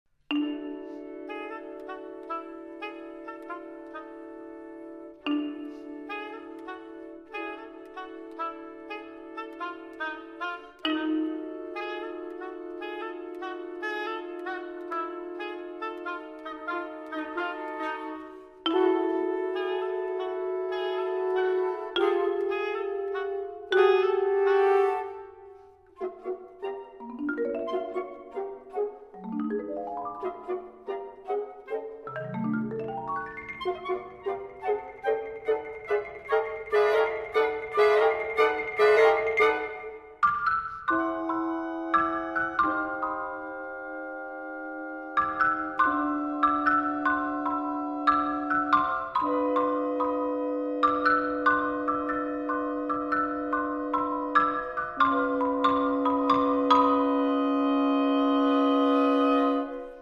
Chamber music
Marimba and Percussion